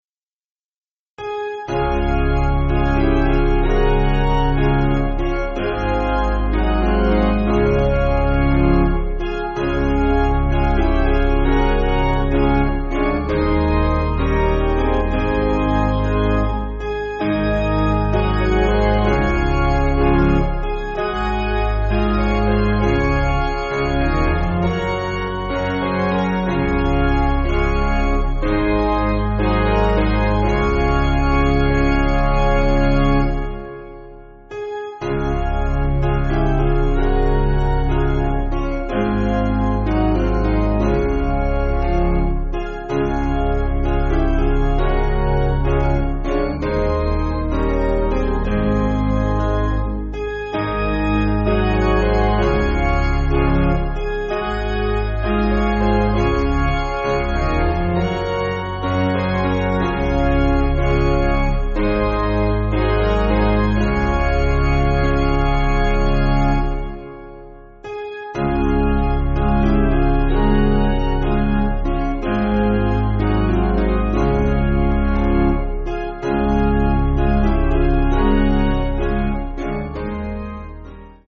Basic Piano & Organ
(CM)   3/Db